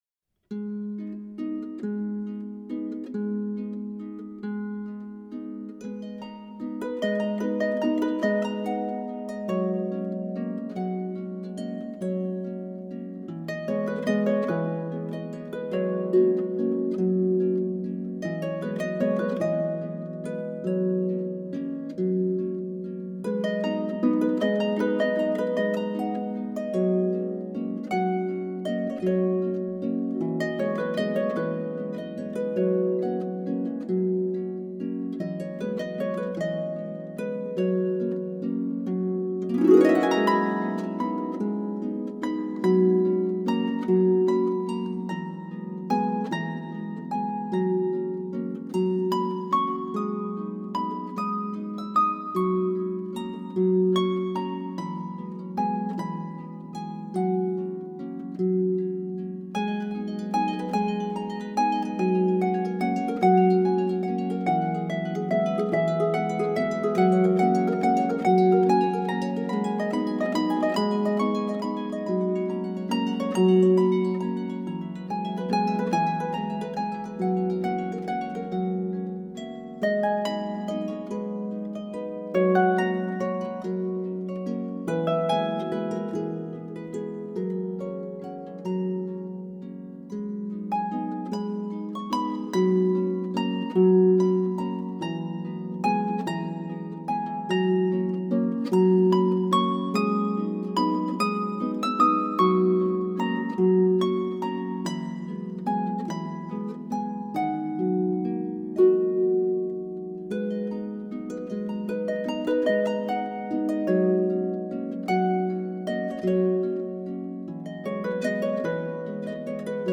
solo lever or pedal harp